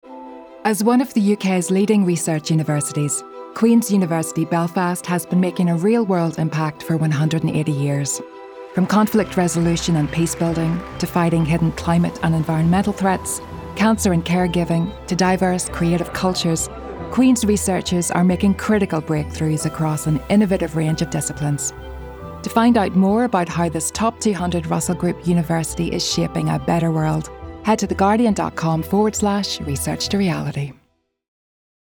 Northern Irish
Warm, Friendly, Informative